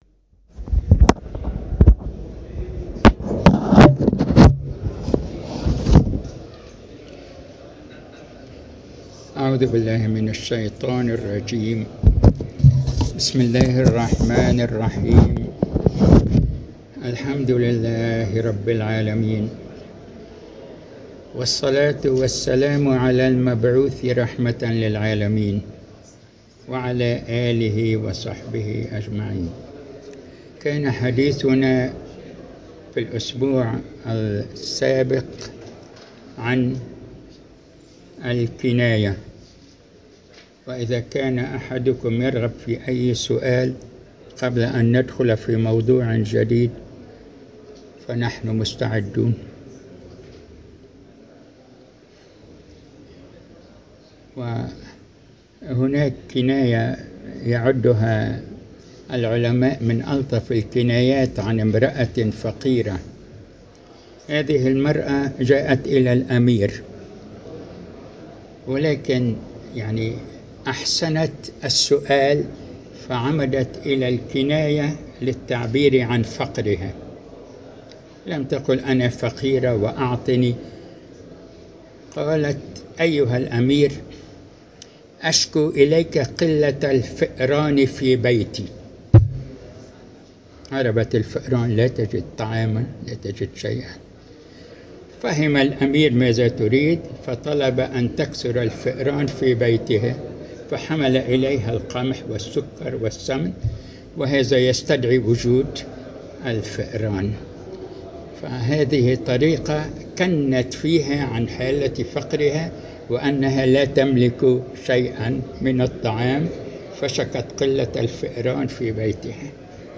المكان : المسجد البحري الموضوع : الإلتفات